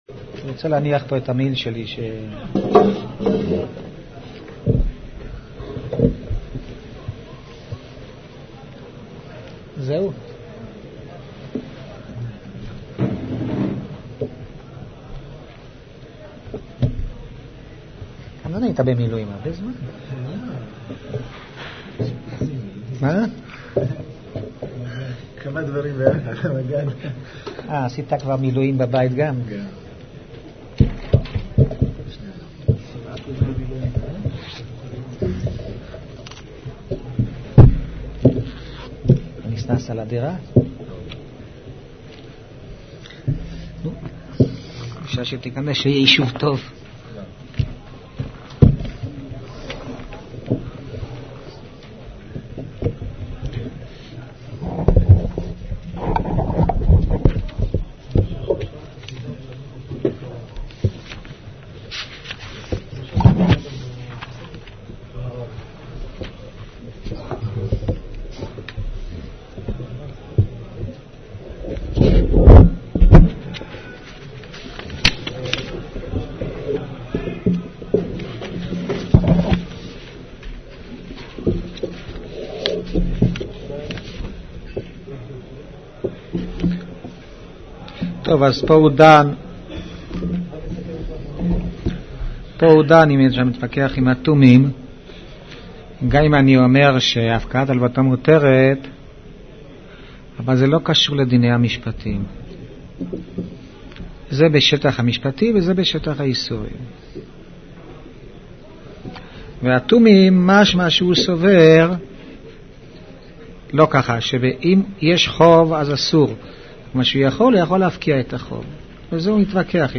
שערי יושר שיעור ב'